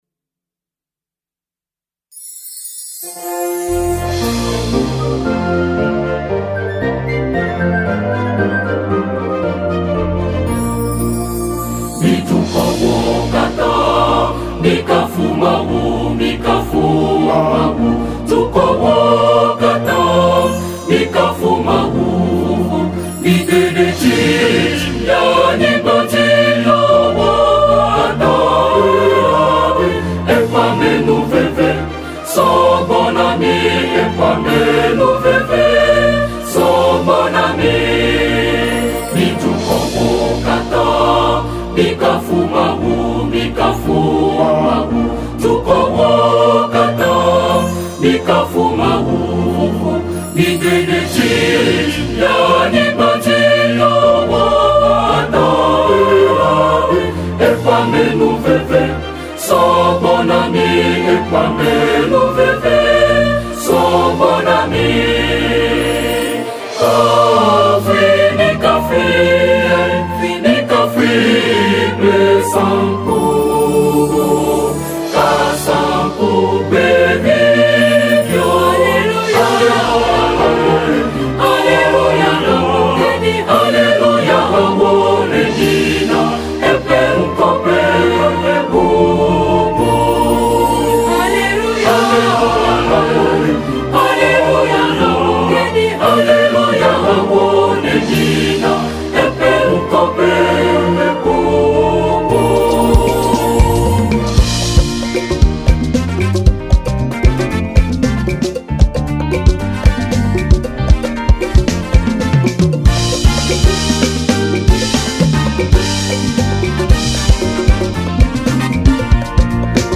• Catégorie : Autres chants liturgiques